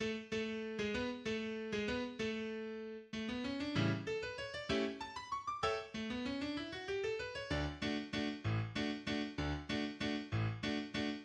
\relative b' { \new PianoStaff << \new Staff { \key b \minor \time 3/4 \set Score.tempoHideNote = ##t \tempo "Tempo di Valse." 2.
The waltz does not have the extended introduction so often favoured by Waldteufel and begins instead with a brief fanfare which announces the waltz's 3/4 time. The refrain is in D major, and is repeated twice.